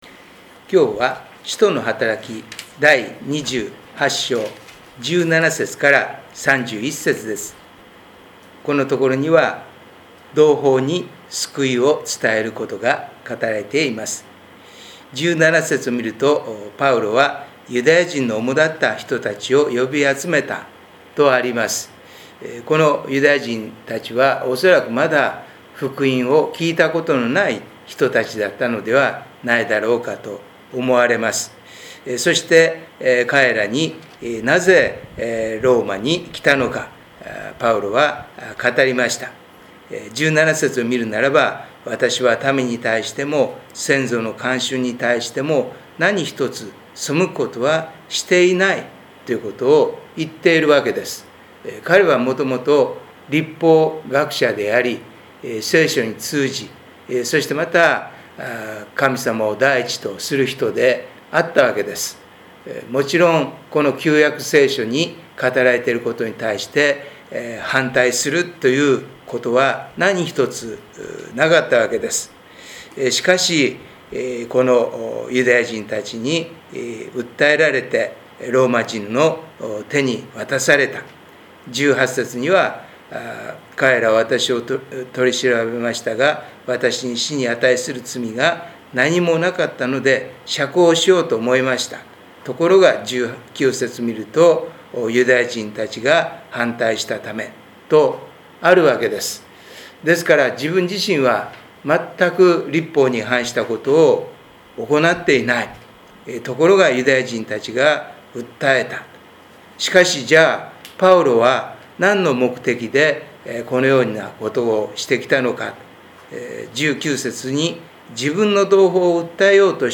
聖書研究祈祷会動画│日本イエス・キリスト教団 柏 原 教 会